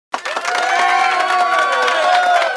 1 channel
Title=applaudissements_03